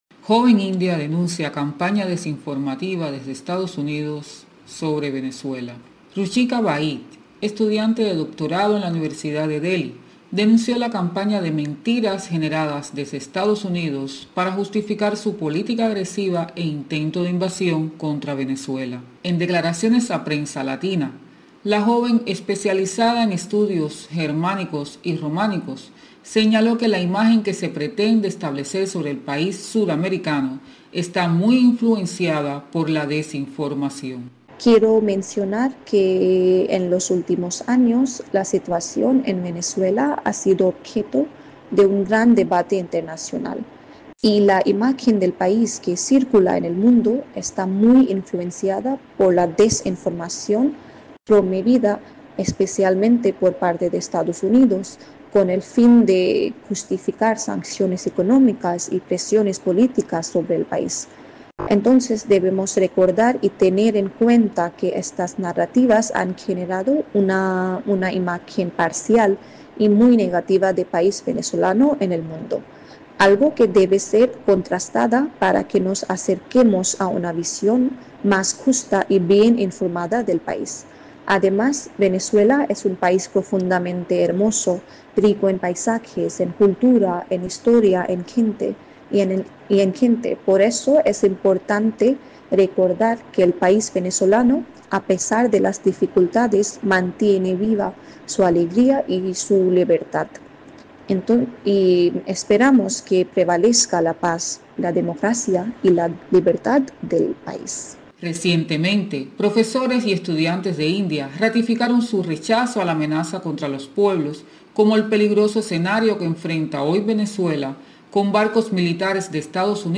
Joven india denuncia campaña contra Venezuela desde EEUU